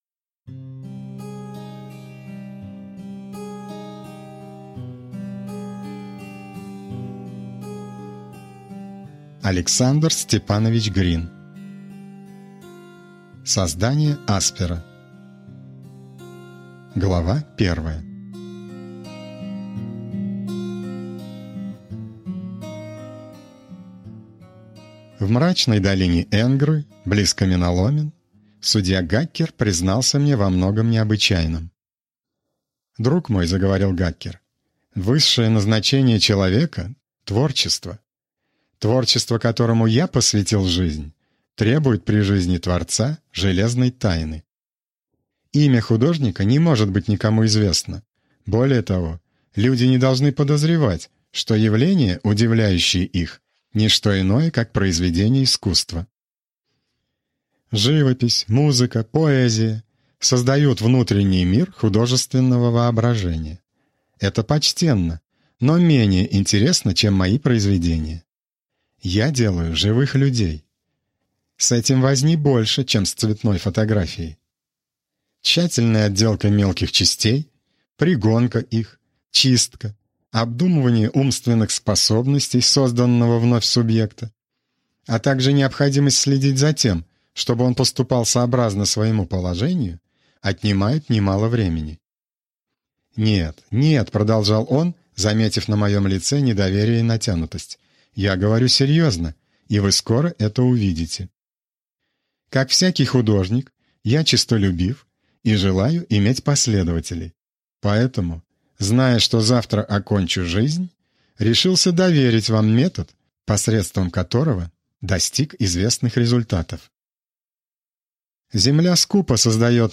Аудиокнига Создание Аспера | Библиотека аудиокниг